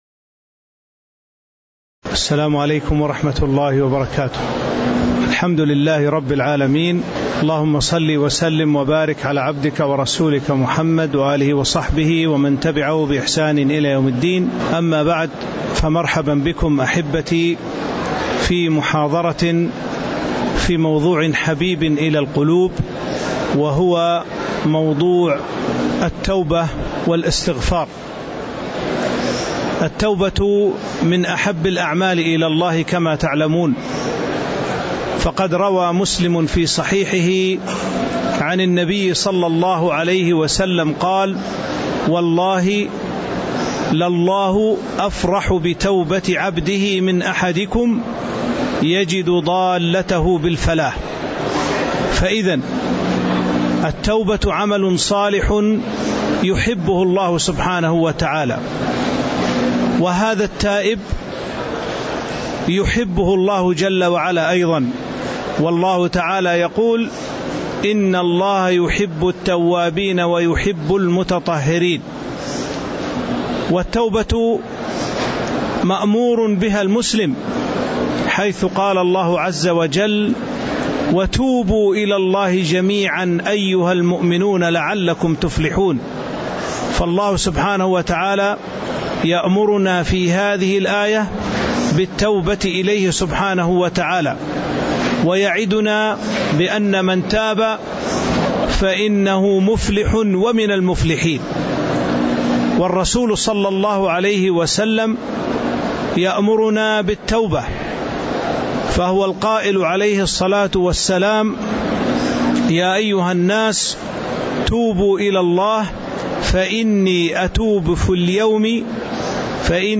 تاريخ النشر ٢٥ ذو الحجة ١٤٤٤ هـ المكان: المسجد النبوي الشيخ